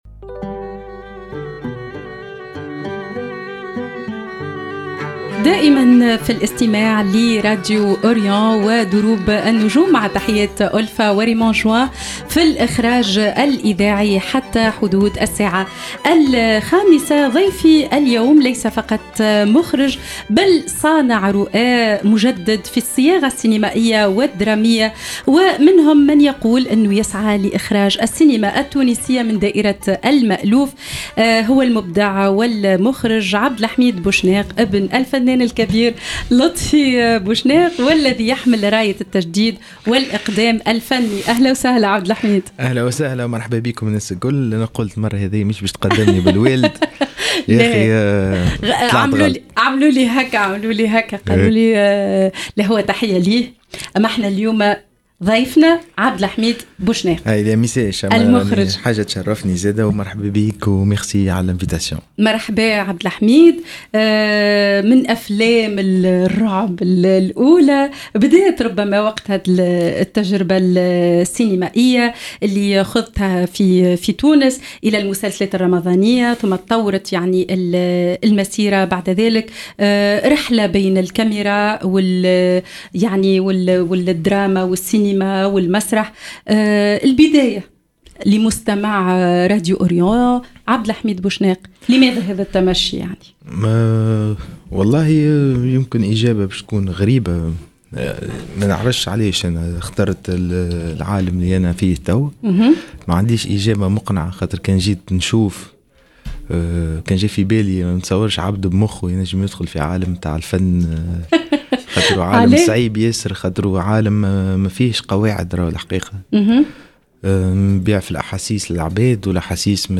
في حلقة جديدة من برنامج دروب النجوم، نلتقي بنجمٍ من نوعٍ خاص، مخرج تونسي آمن بأن الفن لا يعرف الحدود، وأن الجرأة في الرؤية هي التي تصنع الفرق.